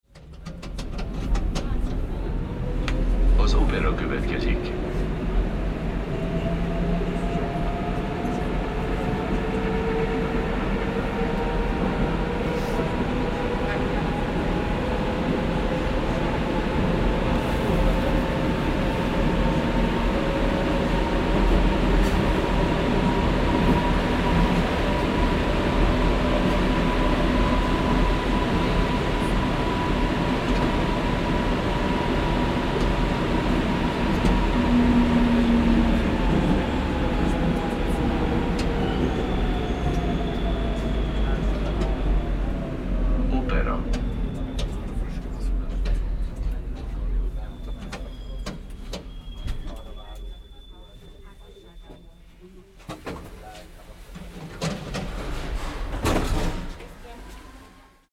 Sound Effects / Street Sounds 11 Apr, 2026 Budapest Metro Arrival Sound Effect With Hungarian Announcement Read more & Download...
Budapest-metro-arrival-sound-effect-with-hungarian-announcement.mp3